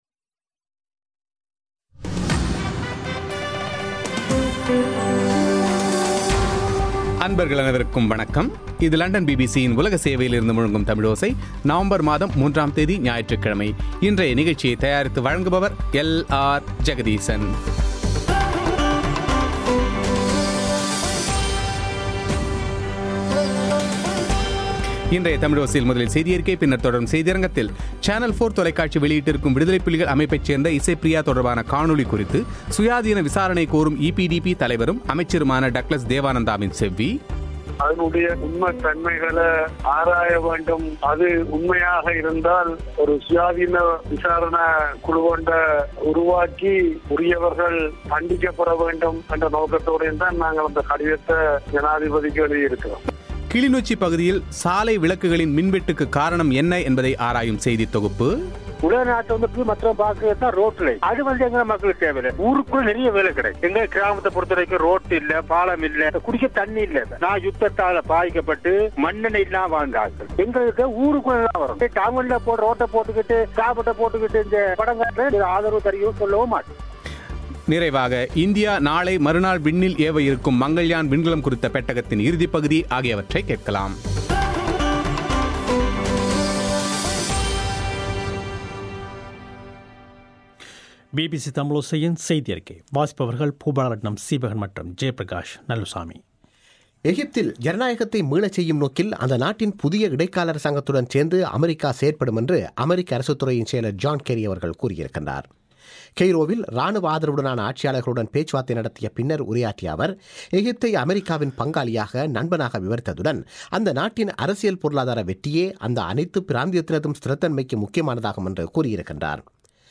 சேனல் 4 தொலைக்காட்சி வெளியிட்டிருக்கும் விடுதலைப்புலிகள் அமைப்பைச் சேர்ந்த இசைப்பிரியா தொடர்பான காணொளி குறித்து சுயாதீன விசாரணை கோரும் ஈபிடிபி தலைவரும் அமைச்சருமான டக்ளஸ் தேவானந்தாவின் செவ்வி;